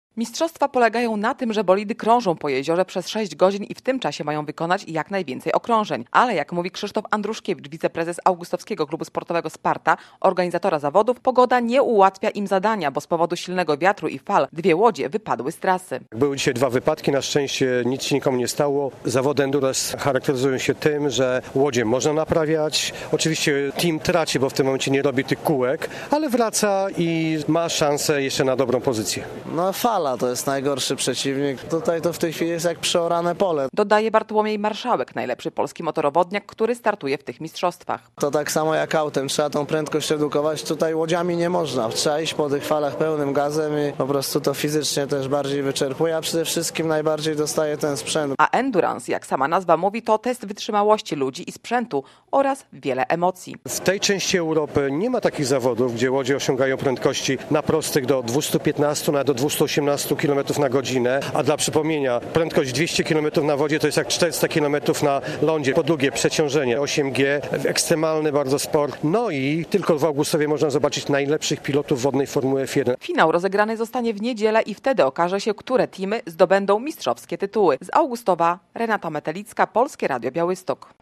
Mistrzostwa łodzi wytrzymałościowych w Augustowie - relacja